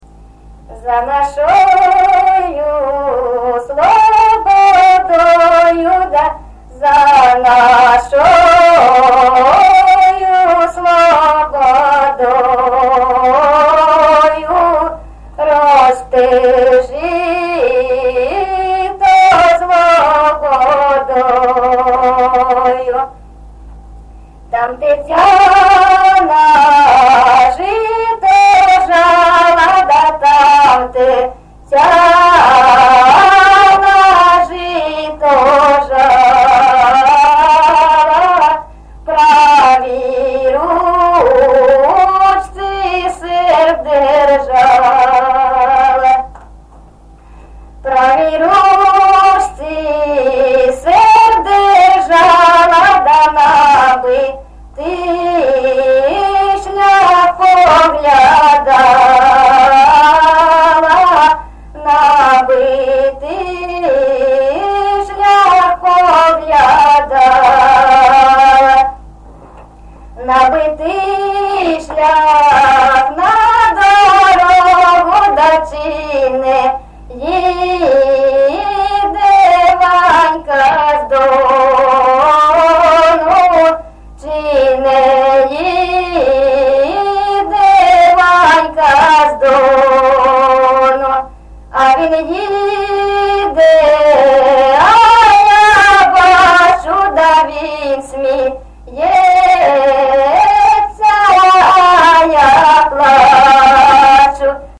ЖанрПісні з особистого та родинного життя
Місце записус-ще Троїцьке, Сватівський район, Луганська обл., Україна, Слобожанщина